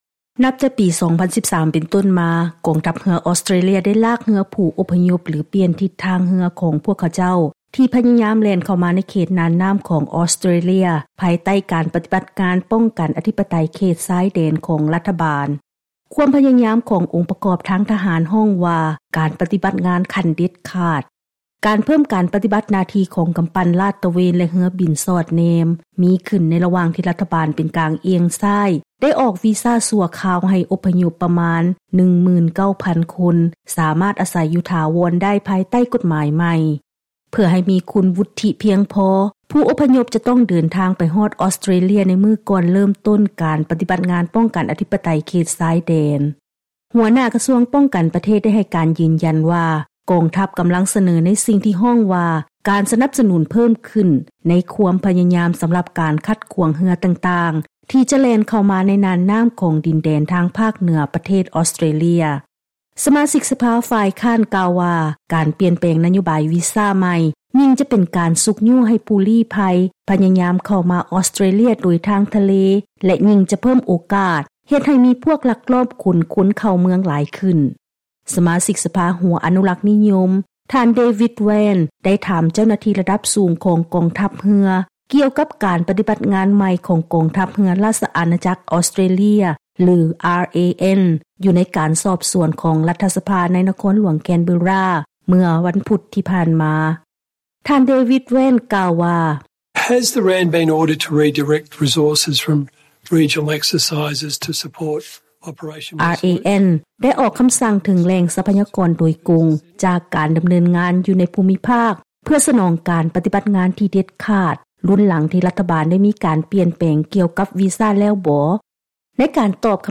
ເຊີນຮັບຟັງລາຍງານກ່ຽວກັບ ການເພີ້ມການປ້ອງກັນຢູ່ຕາມຊາຍແດນທາງທະເລຂອງອອສເຕຣເລຍ ລຸນຫຼັງມີນະໂຍບາຍໃໝ່ກ່ຽວກັບການປ່ຽນແງວີຊາ